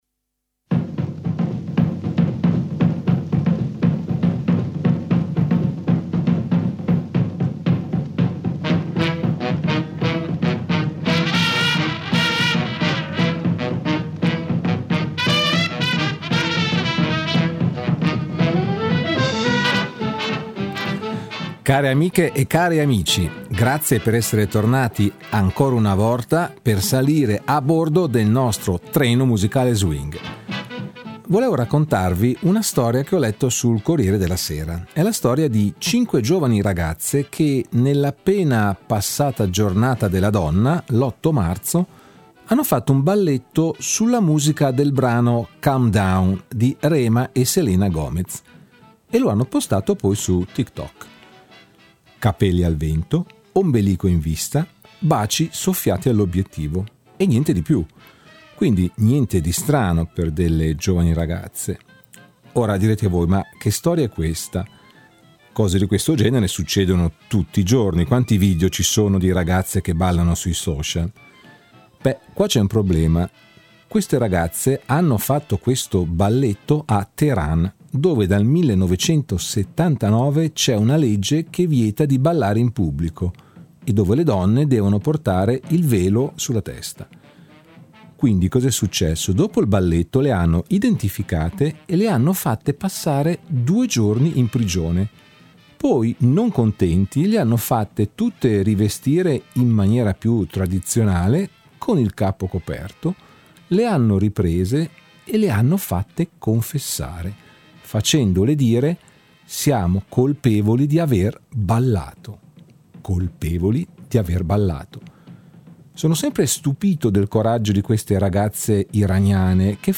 Lo swing e’ una musica già di per se’ allegra , spensierata , festosa leggera ed ottimista ma i brani scelti per questa mia ultima puntata lo sono ancora di più!